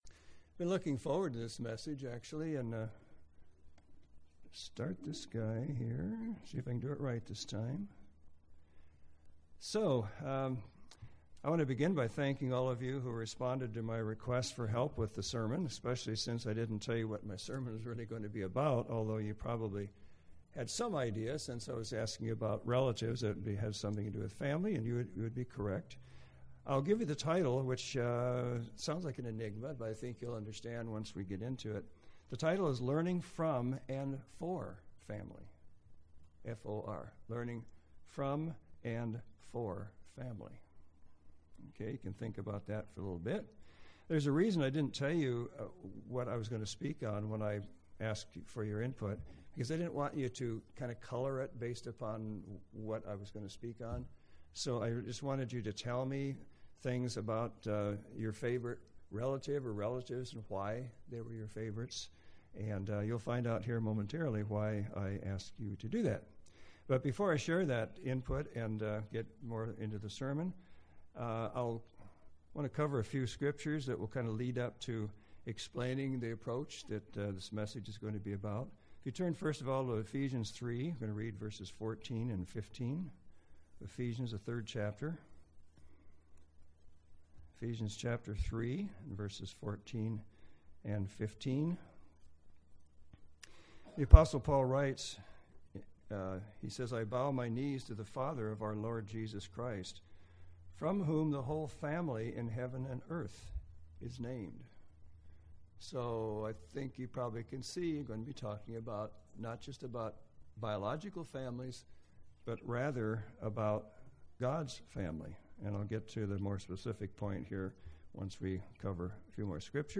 Introduction – I will begin by thanking all of you who responded to my request for help with this sermon, especially since I didn’t tell you what my sermon was about or why I wanted your input.